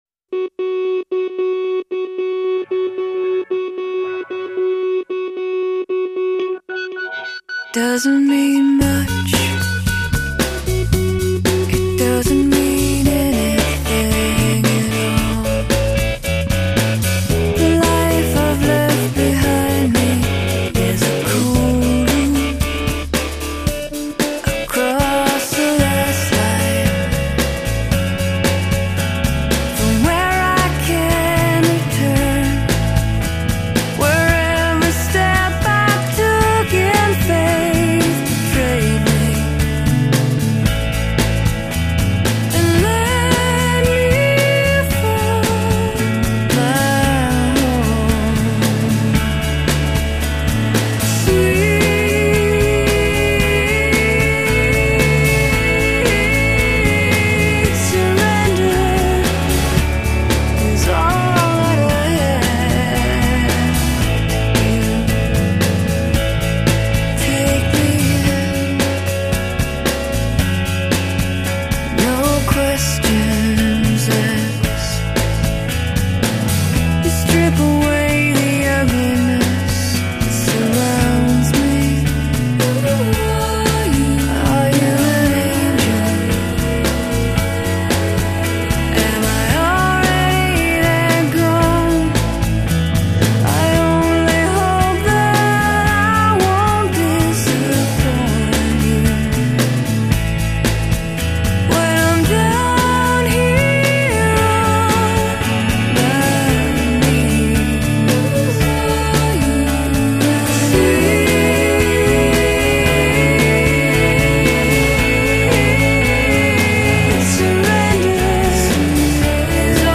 天籁美声
她的唱腔中带着标志性的鼻音，轻柔地，舒缓地衬托出一种难以言传的味道